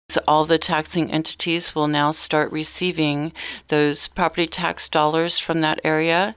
Frampton says several taxing entities will benefit from the early payoff.